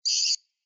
PixelPerfectionCE/assets/minecraft/sounds/mob/rabbit/hurt4.ogg at mc116